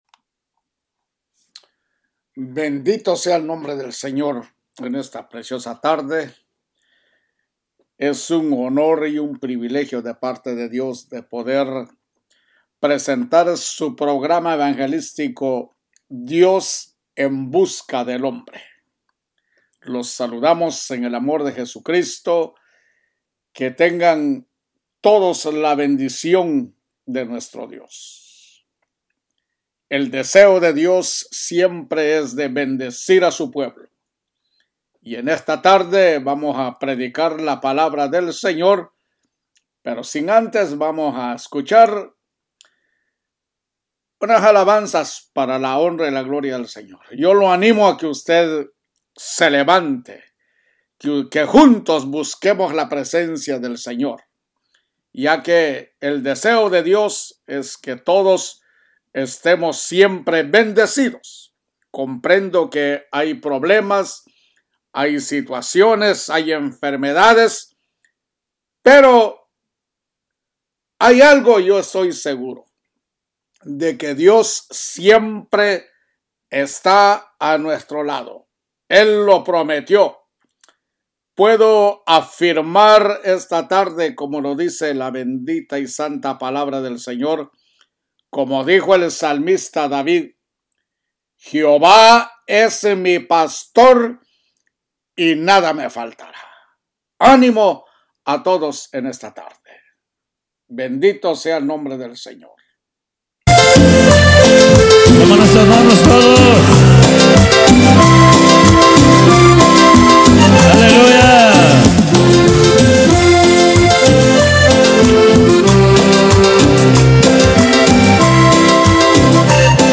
EL EVANGELIO SEGUN PABLO PREDICA #2 PARTE #2
EL-EVANGELIO-SEGUN-PABLO-PREDICA-2-PARTE-2.mp3